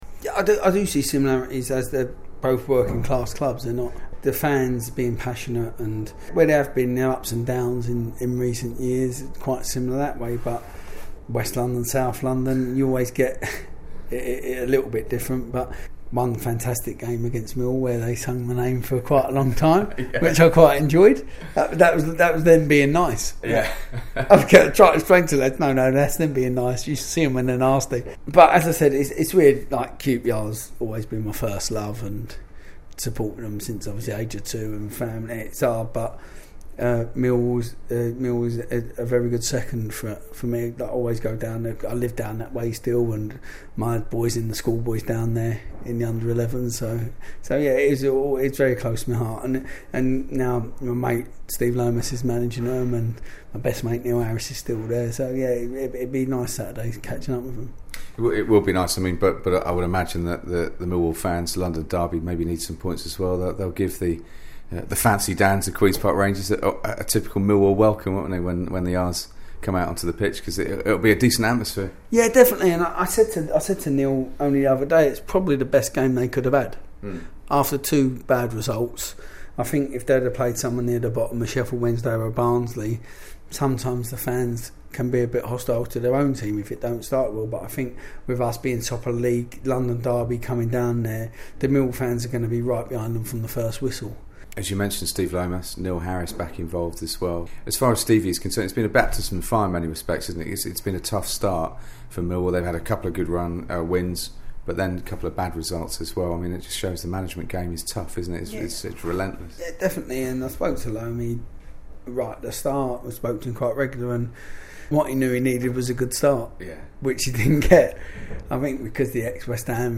Ex Millwall and QPR midfielder talks about his clubs, thoughts on Neil Harris and happy memories on BBC London 949